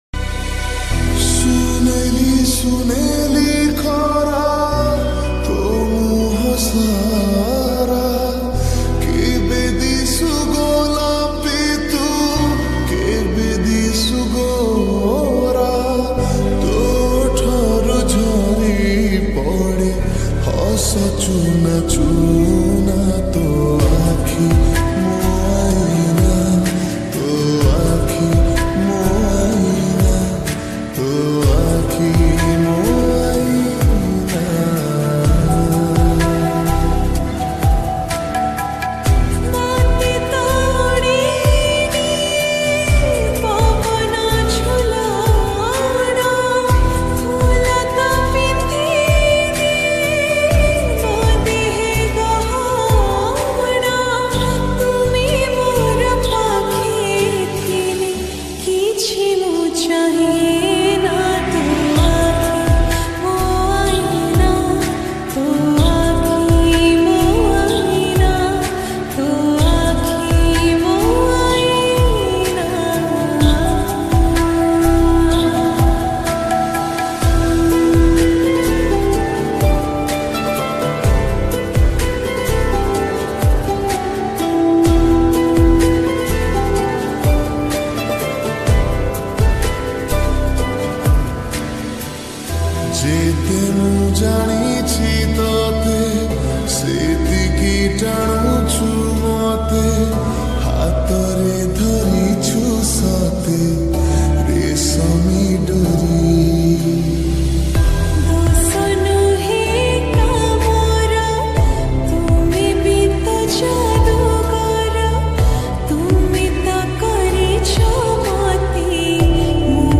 Category : odia lofi song